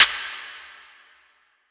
Snare (3).wav